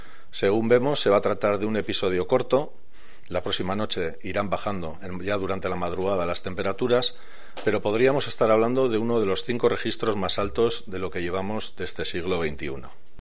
Ricardo Ituarte, director de emergencias del gobierno vasco